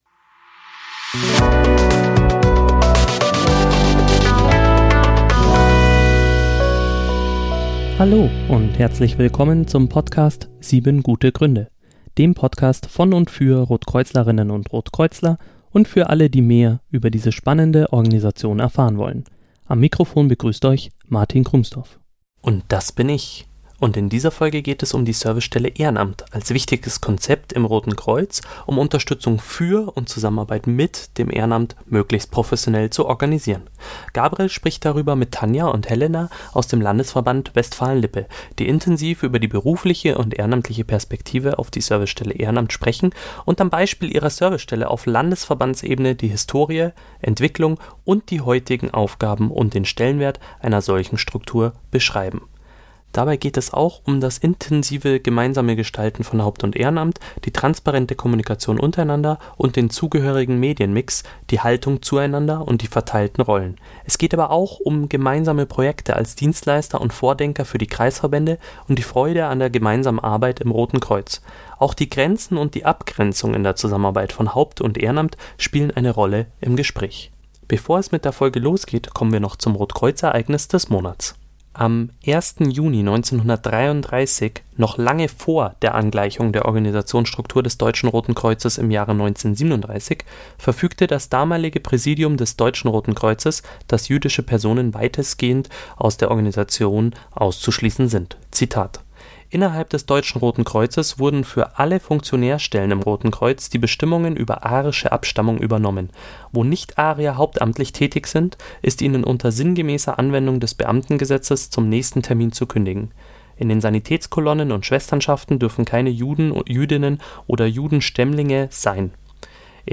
Auch die Grenzen und die Abgrenzung in der Zusammenarbeit von Haupt- und Ehrenamt spielen eine Rolle im Gespräch.